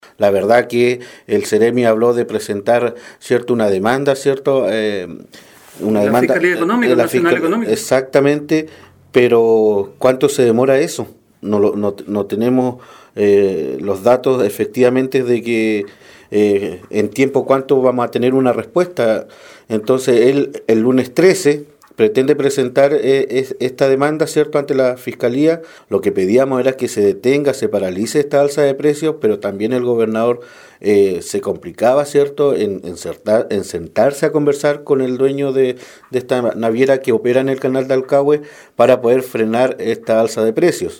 13-CONCEJAL-OSCAR-GALLARDO-2.mp3